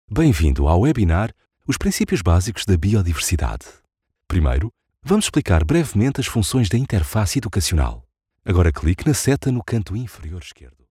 I have a deep low male tone, calm and smooth, usually chosen for corporate, elearning, explainers, narration and commercials.
Portuguese Elearning
Words that describe my voice are Portuguese, deep, low tone.